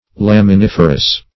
Search Result for " laminiferous" : The Collaborative International Dictionary of English v.0.48: Laminiferous \Lam`i*nif"er*ous\, a. [Lamina + -ferous.]
laminiferous.mp3